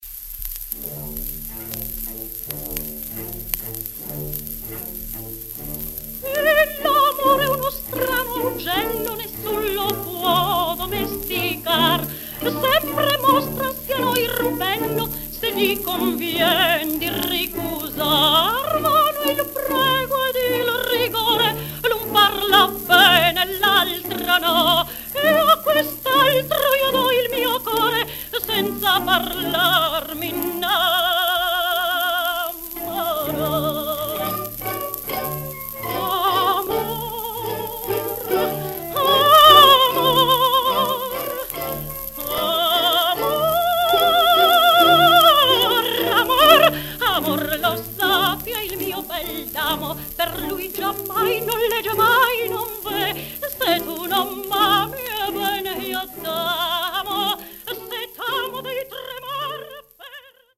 1930年ロンドン録音